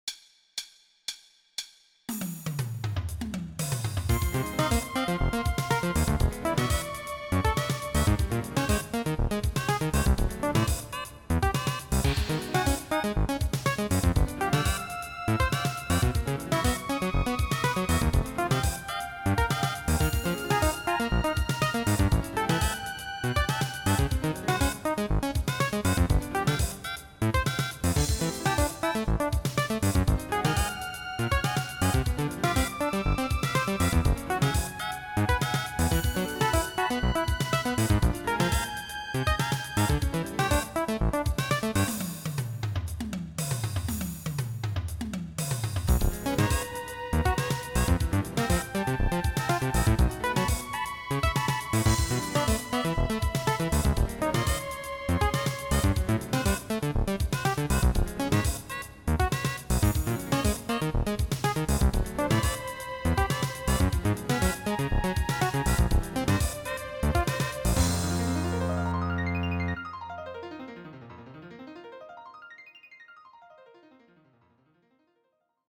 16beat